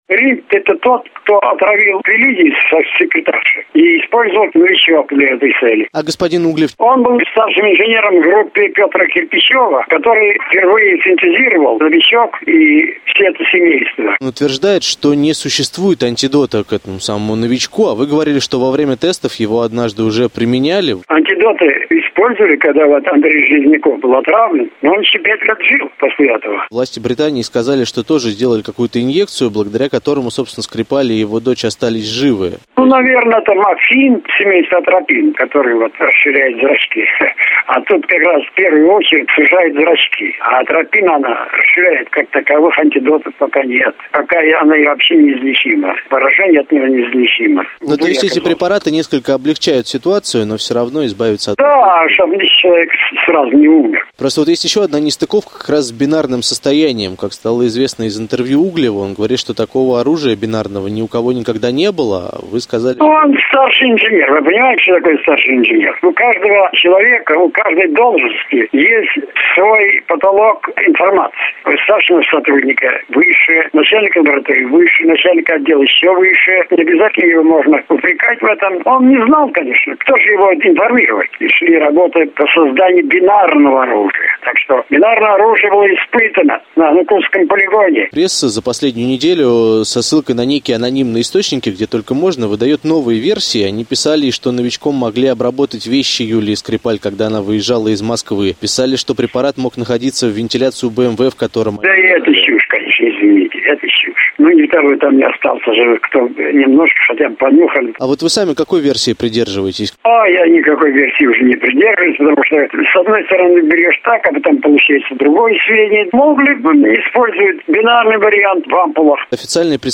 Химик Вил Мирзаянов в интервью «Ъ FM» — об истории «Новичка»